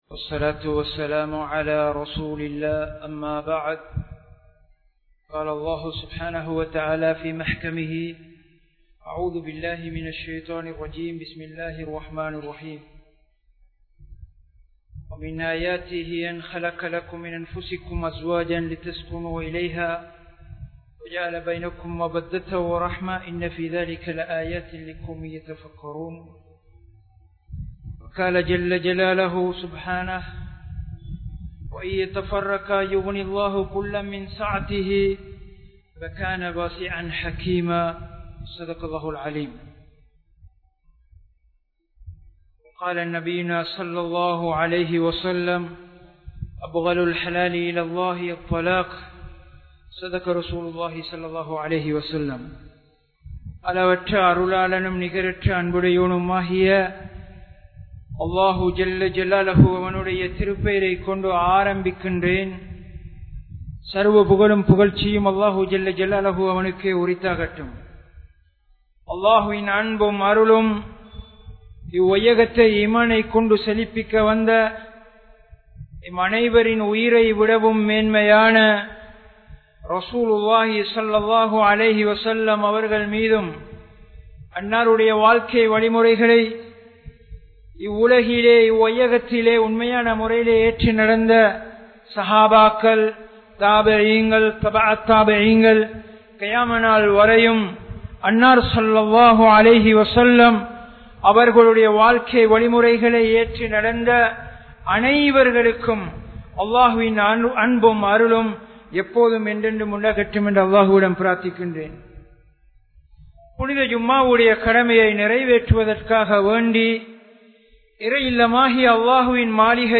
Eemaan Thaarien Panpuhal (ஈமான்தாரியின் பண்புகள்) | Audio Bayans | All Ceylon Muslim Youth Community | Addalaichenai
Wellampittiya, Sedhawatte, Ar Rahmath Jumua Masjidh